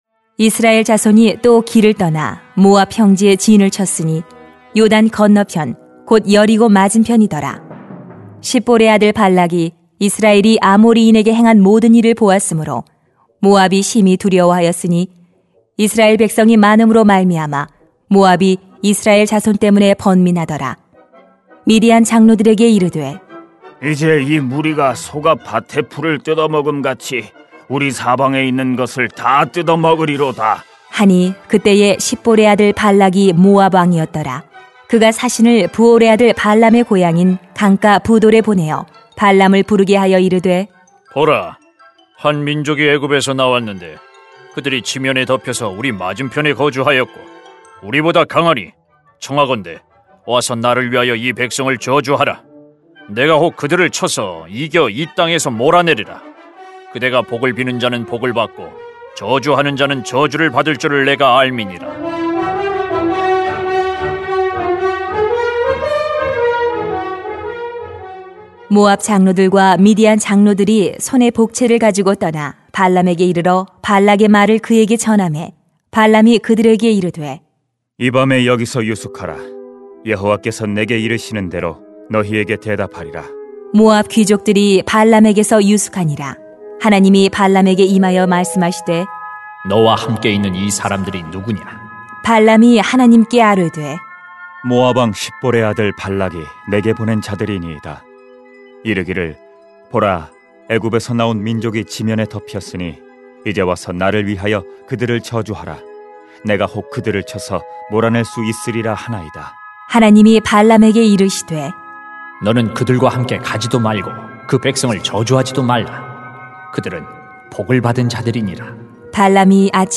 민 22:1 -20] 결정적인 시험을 조심해야 합니다 > 새벽기도회 | 전주제자교회